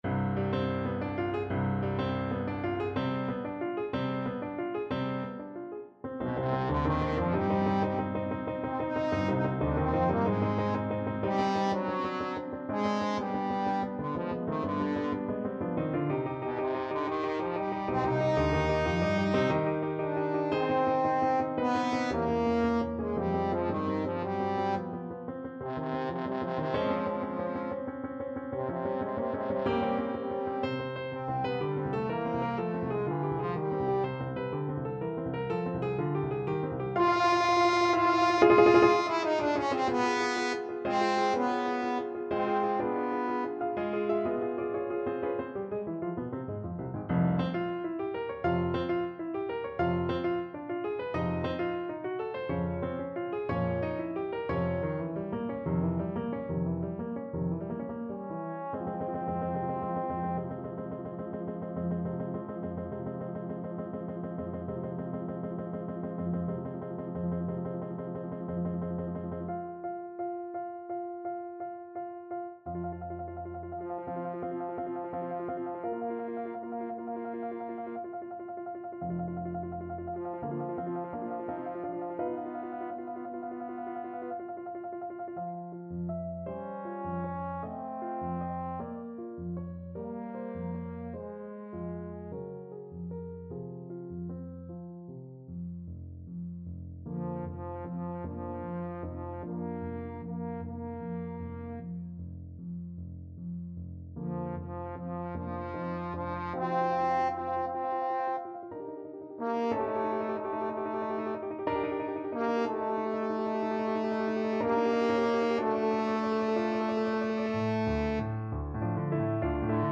Trombone version
Schnell und wild = 185
9/8 (View more 9/8 Music)
Ab3-F5
Classical (View more Classical Trombone Music)